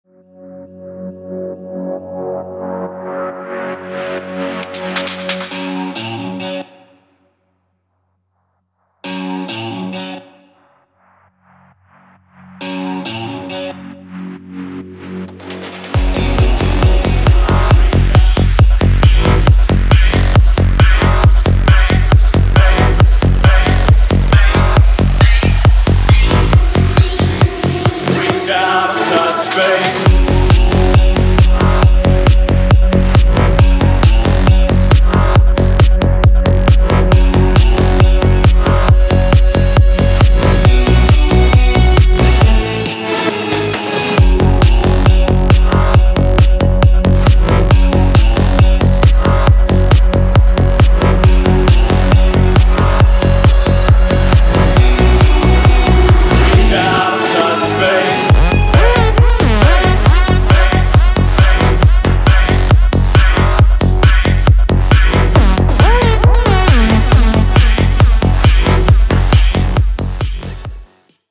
• Качество: 320, Stereo
гитара
мужской вокал
рок
Альтернативный рок